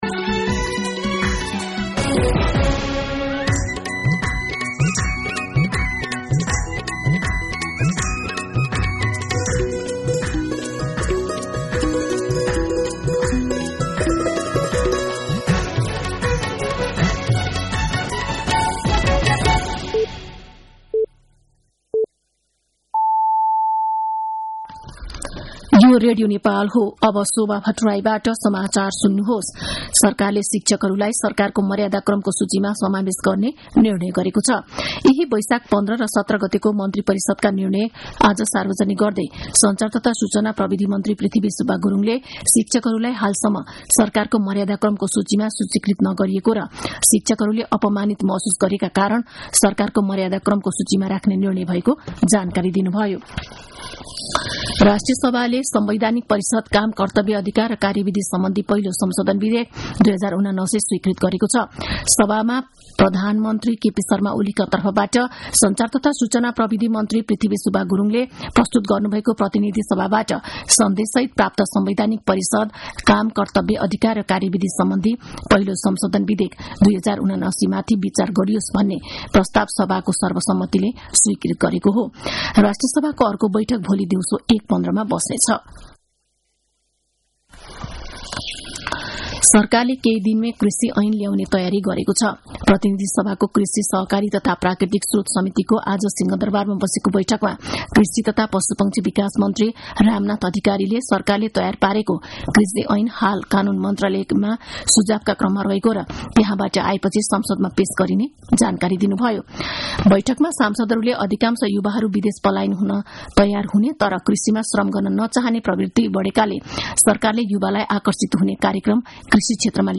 साँझ ५ बजेको नेपाली समाचार : २१ वैशाख , २०८२
5.-pm-nepali-news.mp3